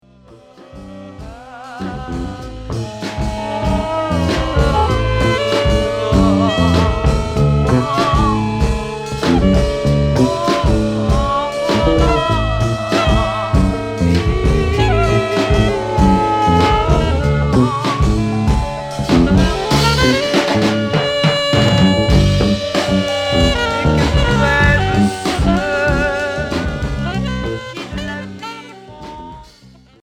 Pop progressif Unique 45t retour à l'accueil